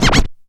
HI SCRATCH 2.wav